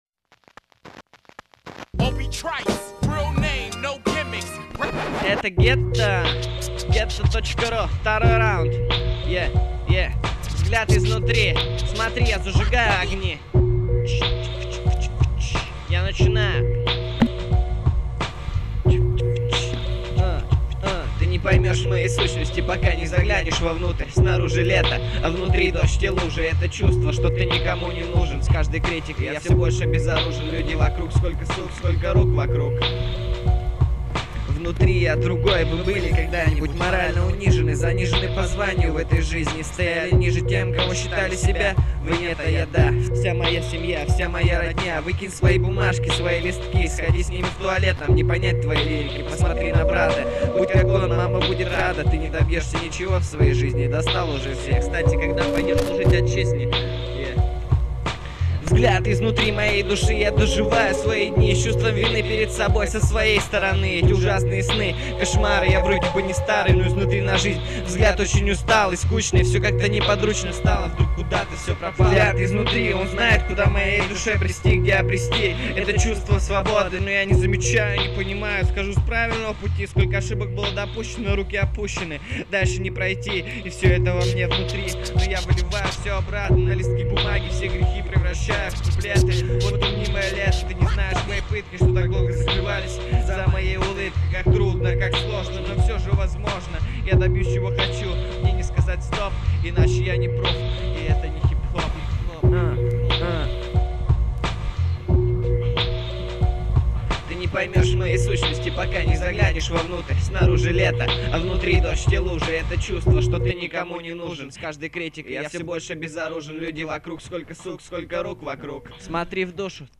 • Жанр: Хип-хоп